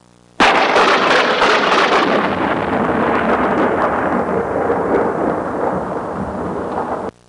Sharp Thunder Sound Effect
Download a high-quality sharp thunder sound effect.
sharp-thunder-1.mp3